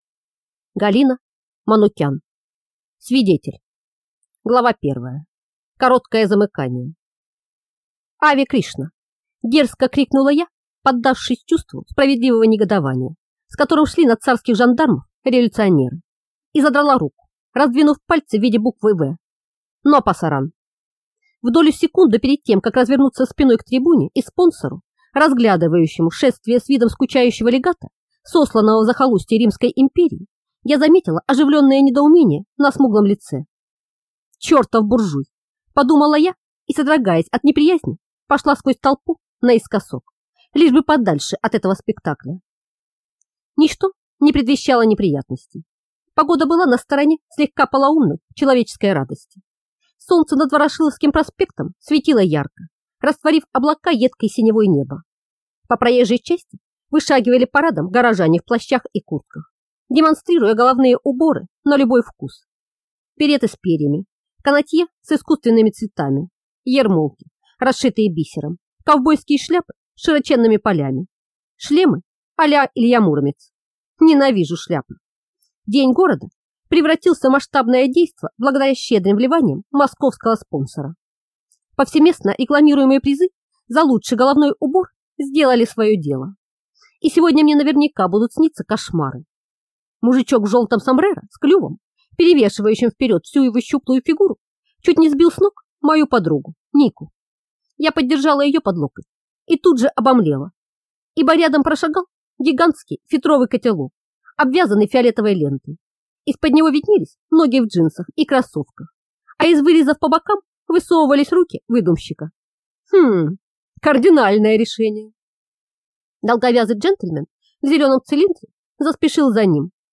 Аудиокнига Свидетель | Библиотека аудиокниг
Прослушать и бесплатно скачать фрагмент аудиокниги